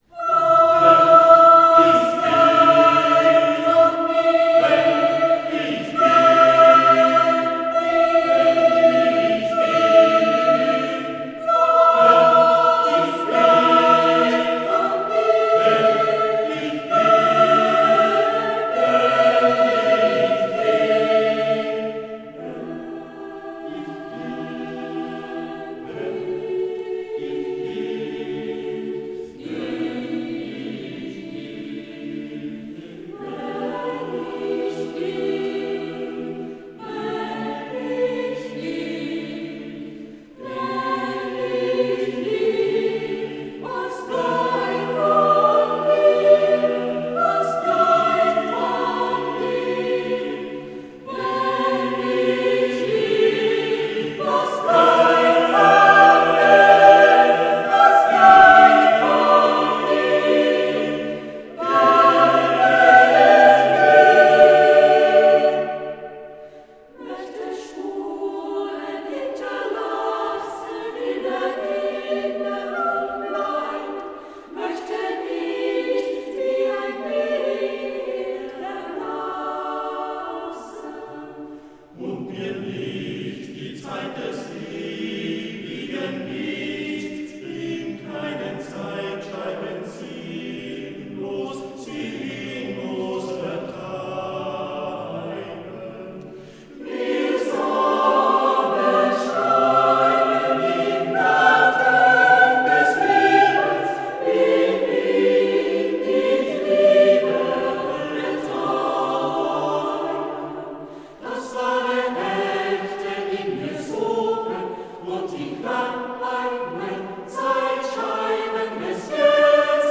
Hier finden Sie das Tondokument der Uraufführung.